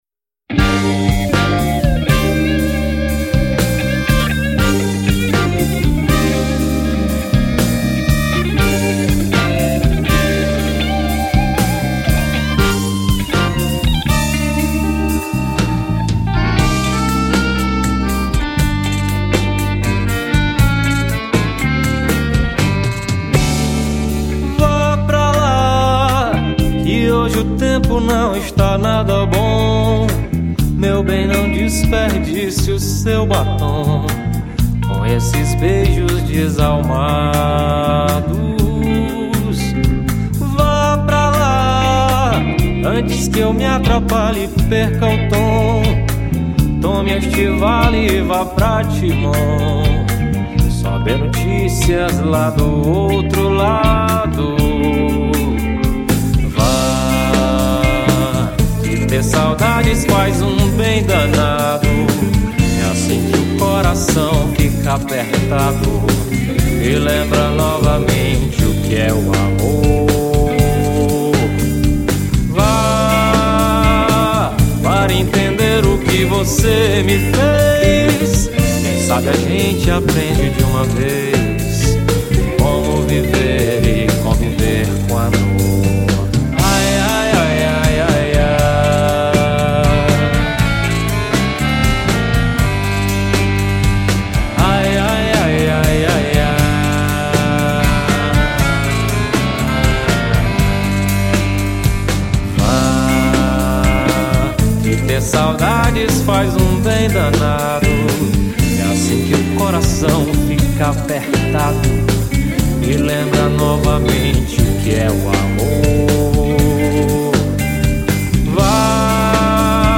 1918   03:27:00   Faixa:     Rock Nacional